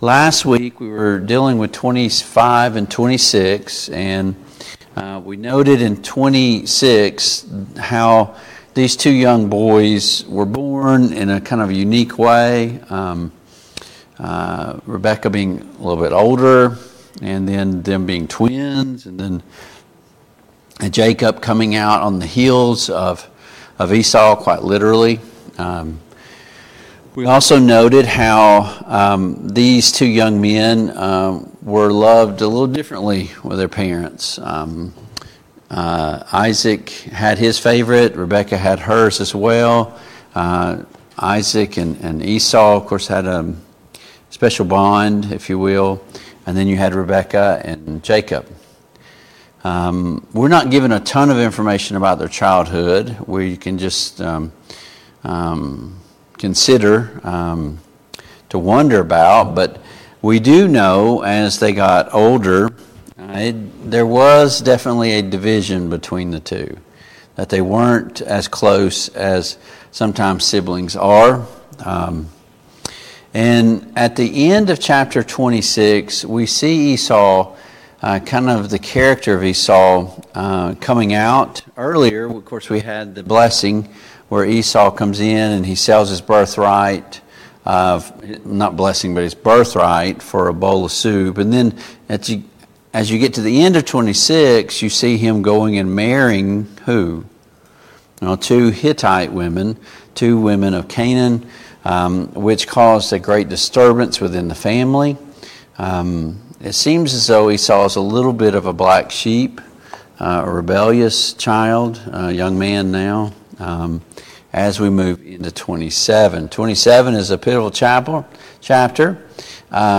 Study of Genesis Passage: Genesis 27 Service Type: Family Bible Hour « Why is Scriptural Authority important? 12.